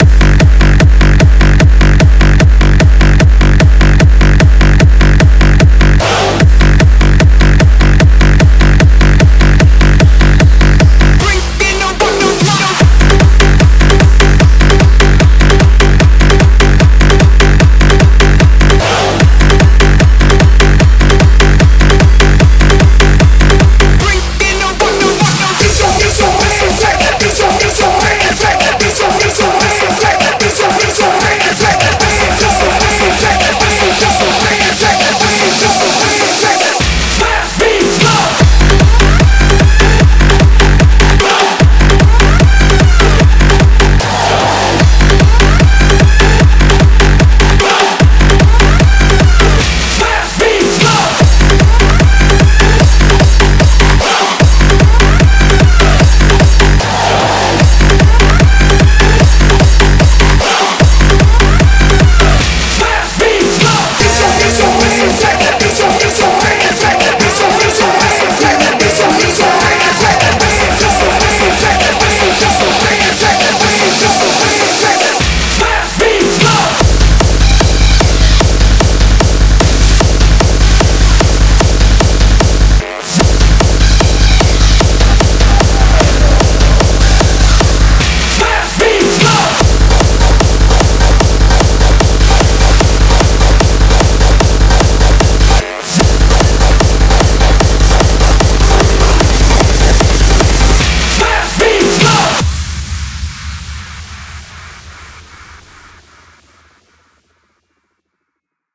试听文件为低音质，下载后为无水印高音质文件